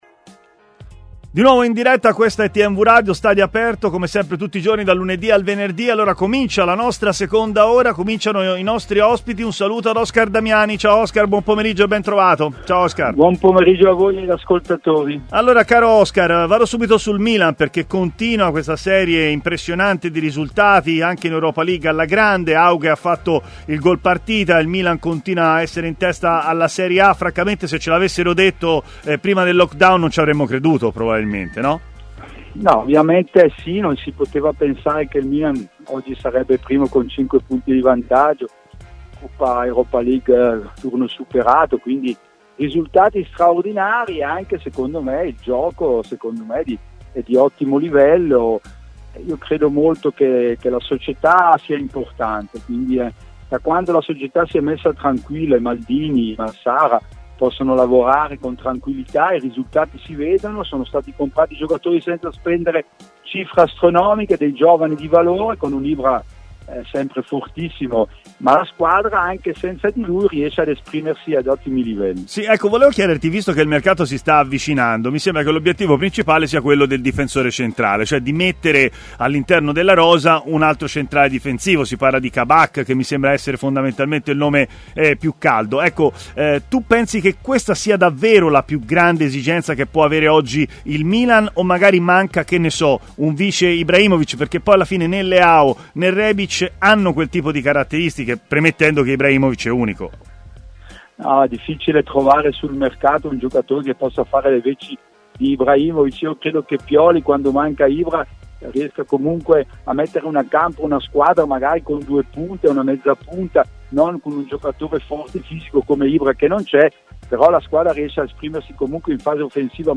intervenuto in diretta a TMW Radio